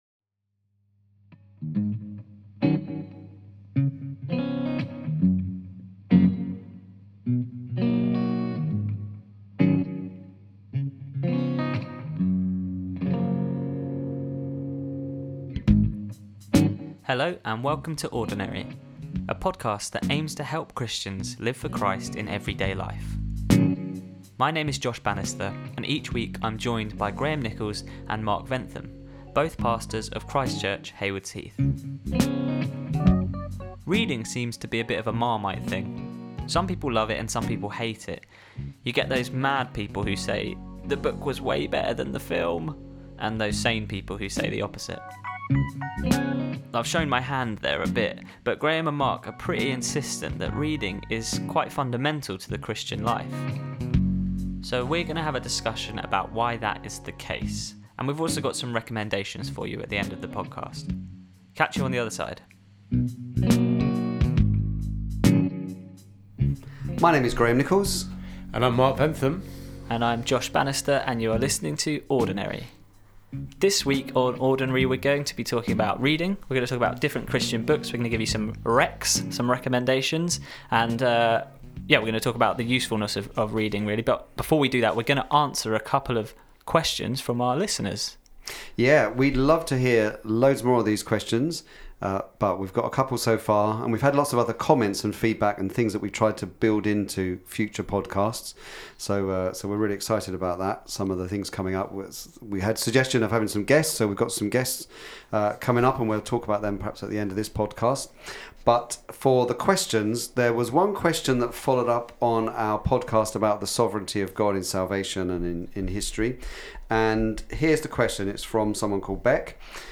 Ordinary-Podcast-episode-24-Why-bother-reading-Q-A.mp3